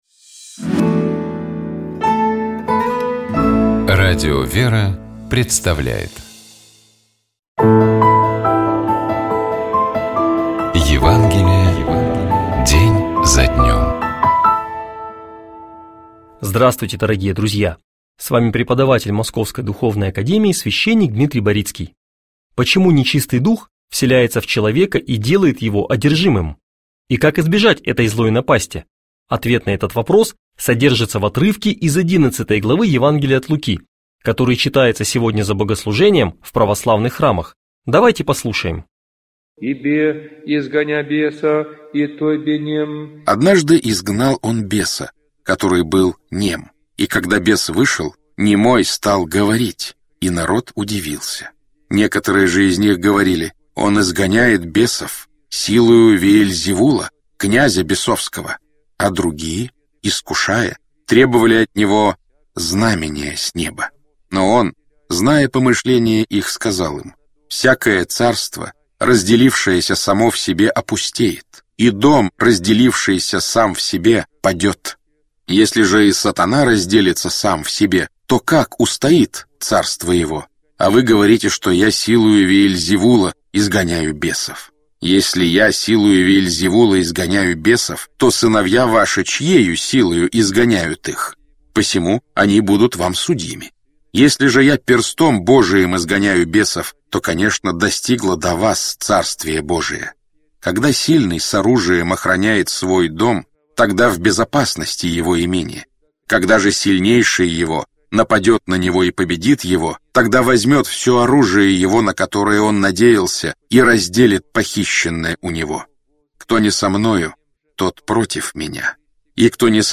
Читает и комментирует
епископ Феоктист ИгумновЧитает и комментирует епископ Переславский и Угличский Феоктист